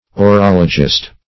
Orologist \O*rol"o*gist\, n. One versed in orology.